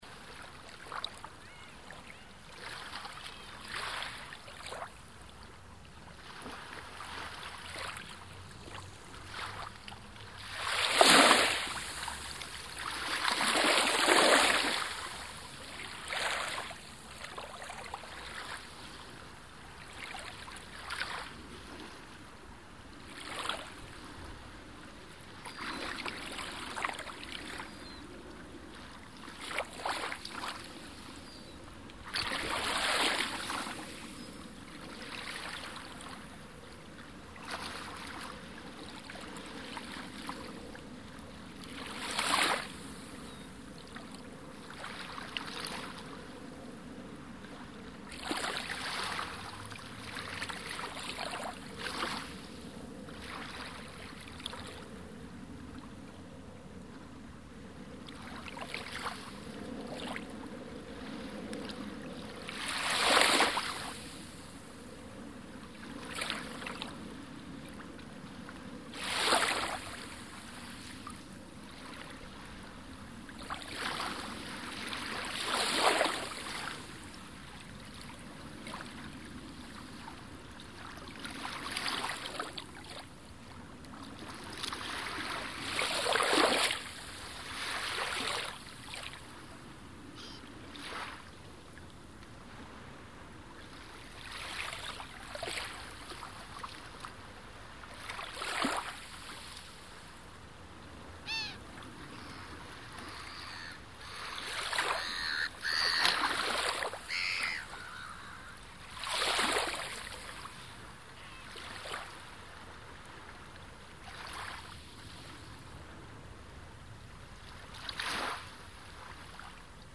Звуки моря, океана